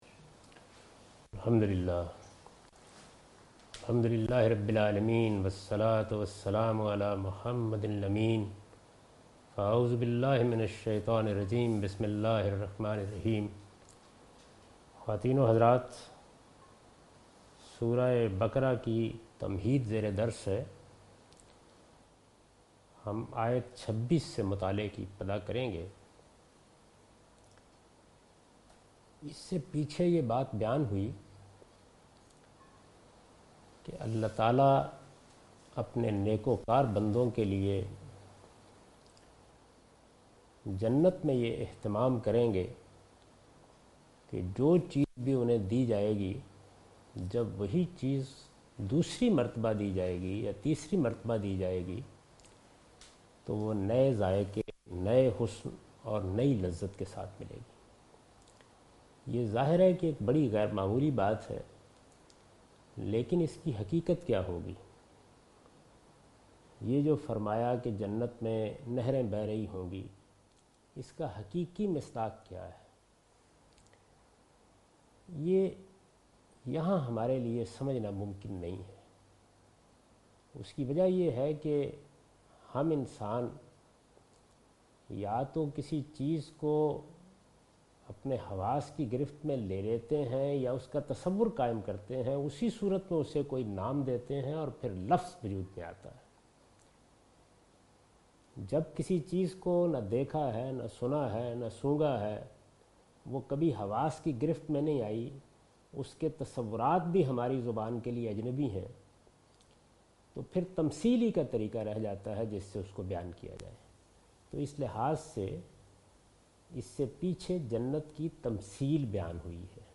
Surah Al-Baqarah - A lecture of Tafseer-ul-Quran – Al-Bayan by Javed Ahmad Ghamidi. Commentary and explanation of verse 26 and 27 (Lecture recorded on 18th April 2013).